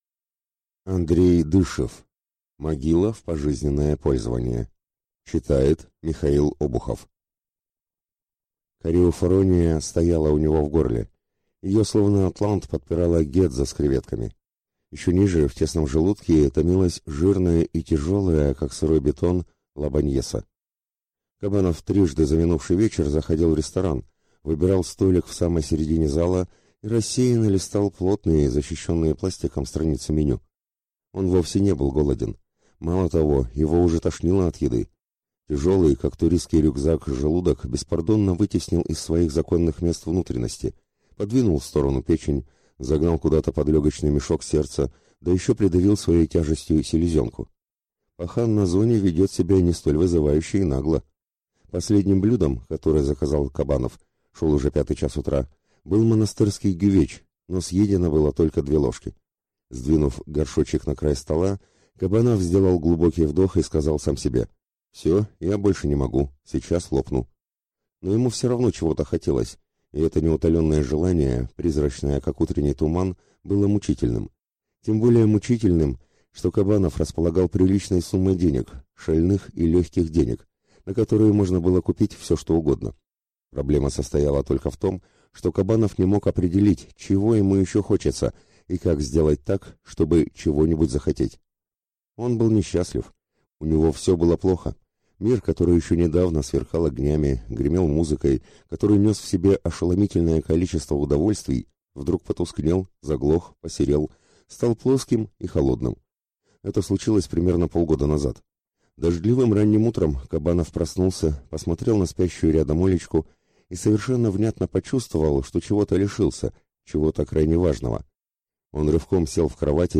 Аудиокнига Могила в пожизненное пользование | Библиотека аудиокниг